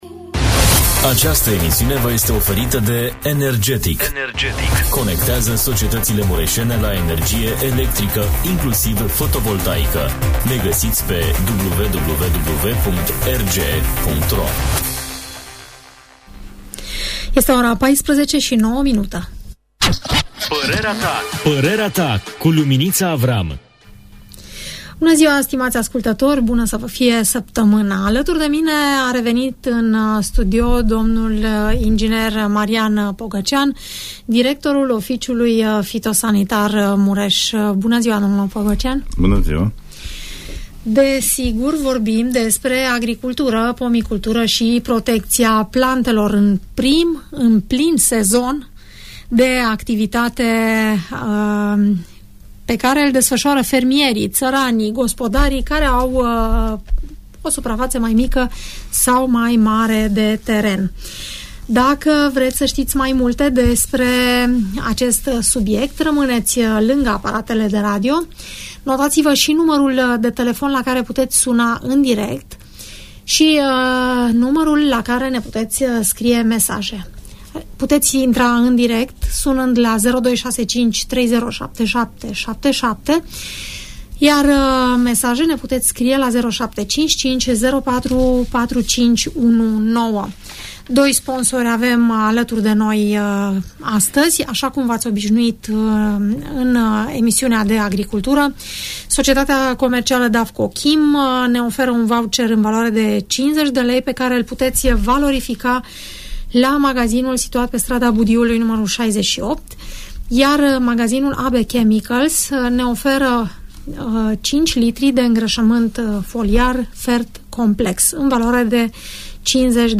Temperatura aerului și a solului, permite efectuarea arăturilor de primăvară, a semănăturilor în marea cultură dar și în legumicultură, precum și aplicarea tratamentelor pentru combaterea bolilor și dăunătorilor. Vrei recolte bogate și sănătoase? Ascultă emisiunea ” Părerea ta”!